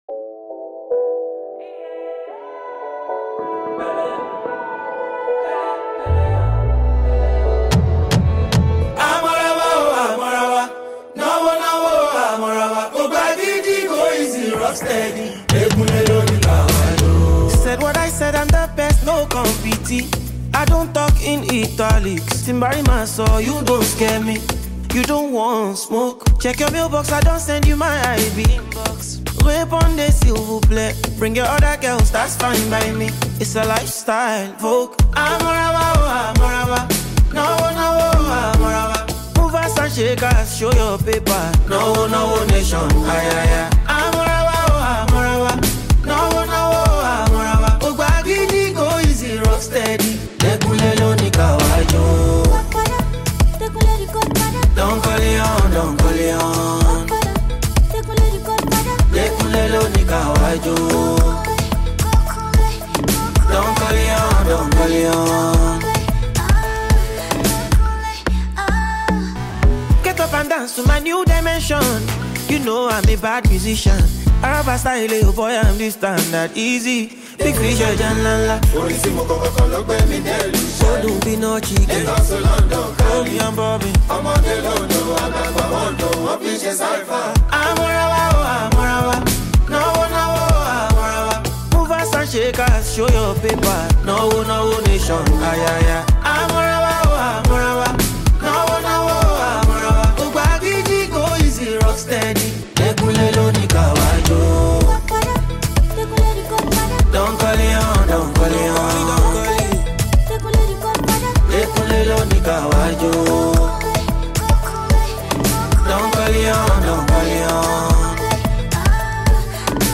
smooth and engaging tune